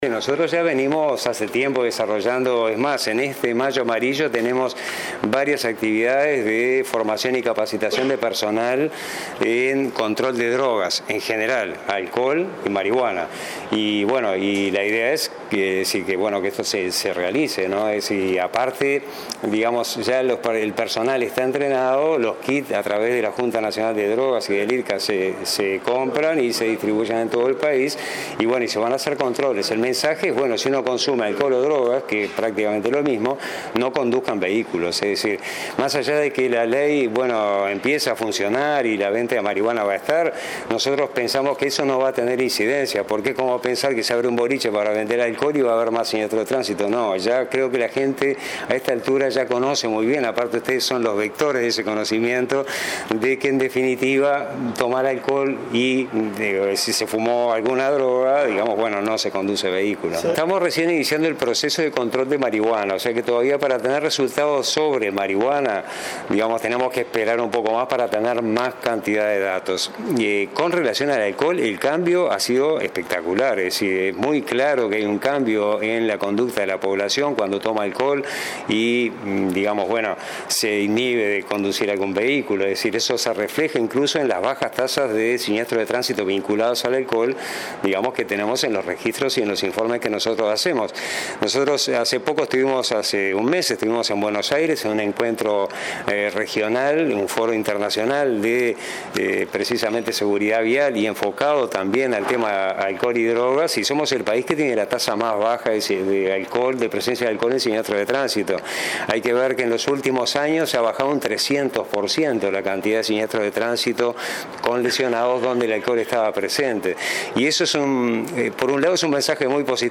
El presidente de Unasev, Gerardo Barrios, sostuvo que, con relación al alcohol, el cambio de conducta de la población ha sido espectacular. Añadió que Uruguay es el país que tiene la tasa más baja de presencia de alcohol en siniestros de tránsito.